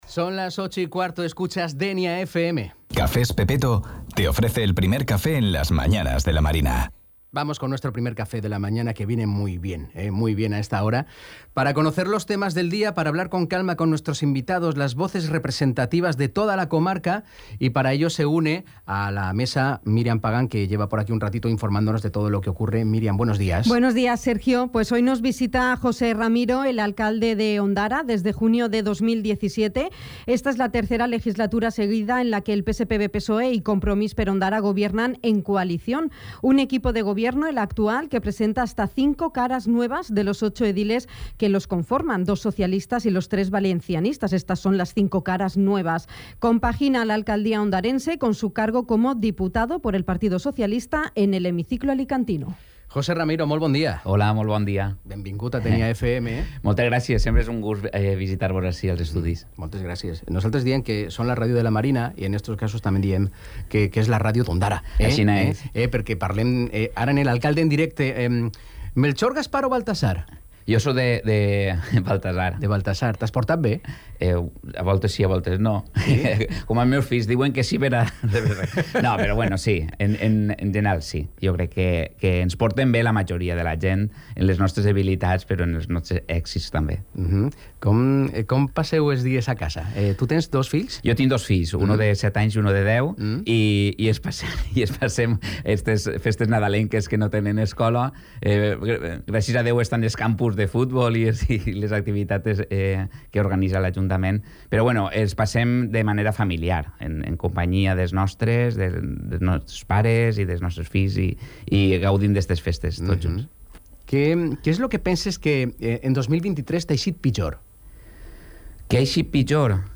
El alcalde de Ondara, el socialista José Ramiro ha visitado el ‘primer café’ de Dénia FM para realizar balance político del ejercicio 2023 y previsiones para 2024.
Entrevista-Jose-Ramiro.mp3